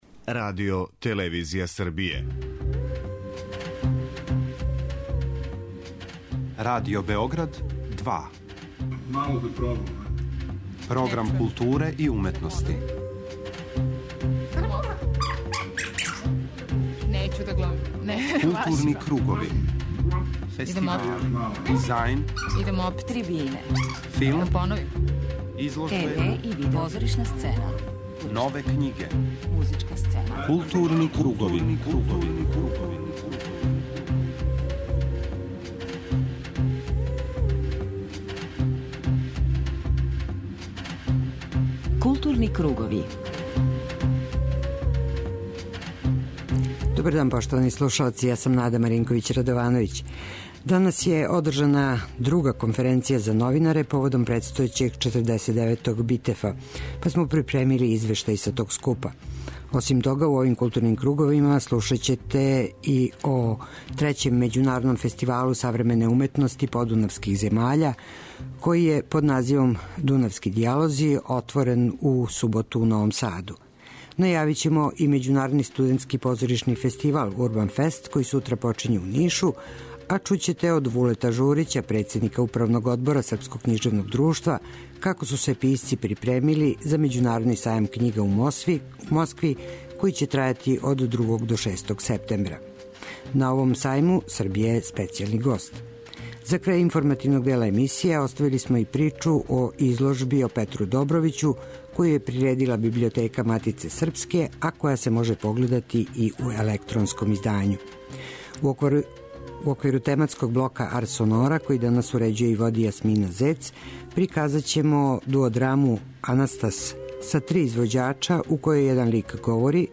преузми : 52.60 MB Културни кругови Autor: Група аутора Централна културно-уметничка емисија Радио Београда 2.